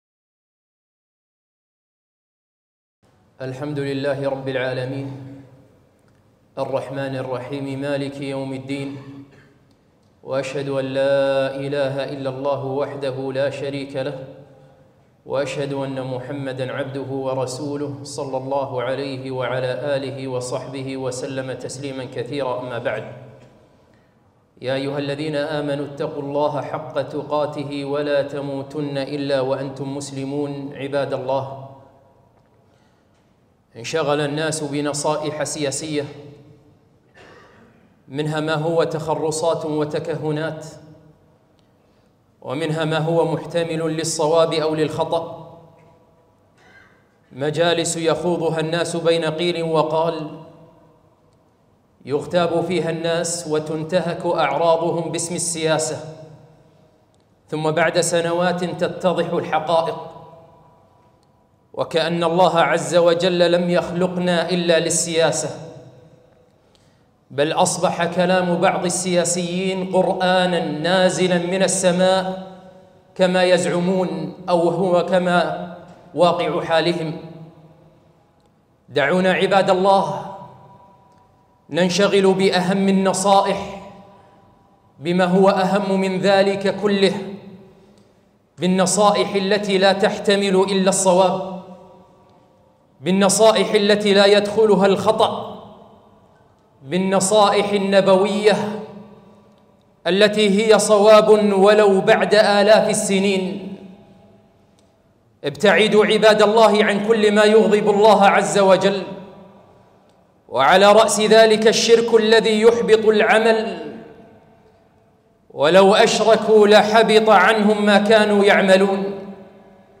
خطبة - نصائح السياسيين أم نصائح خاتم المرسلين؟